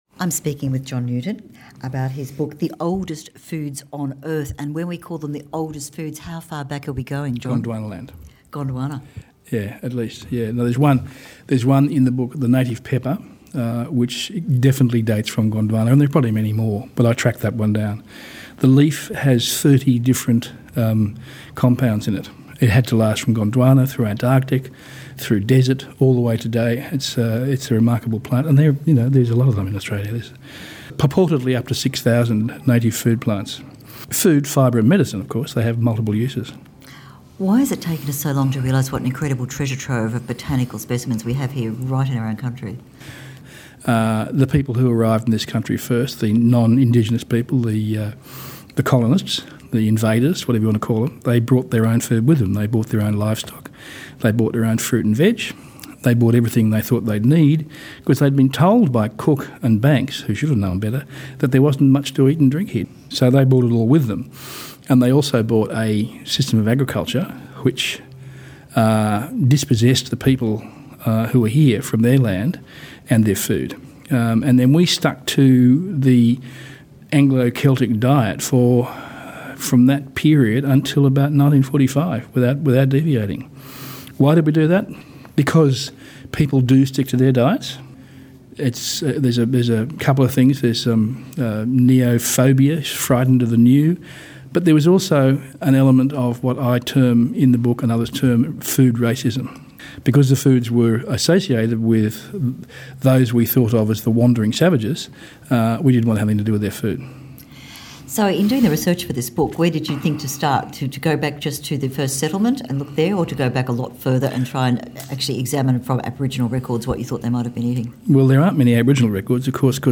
Books and Authors - live interviews, Cooking and food
Recorded at Sydney Writers Festival 2016